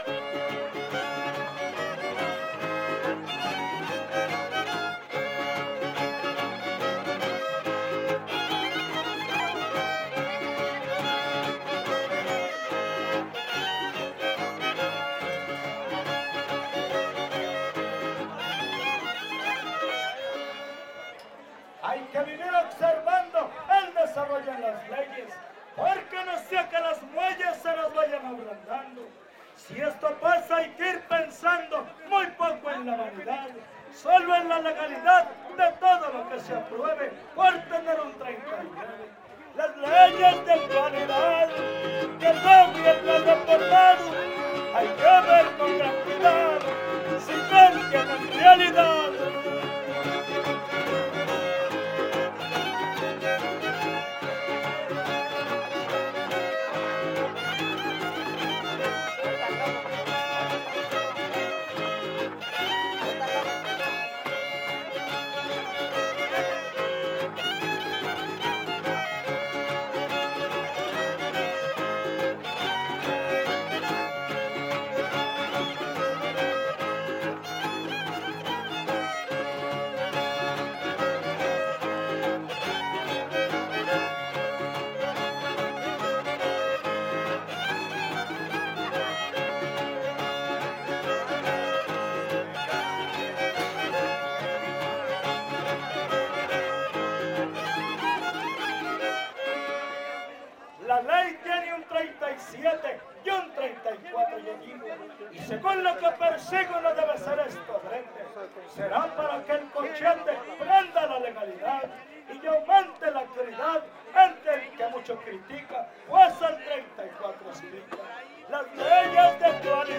Poesía popular Huapango arribeño
Violín Guitarra Vihuela
Topada ejidal: Cárdenas, San Luis Potosí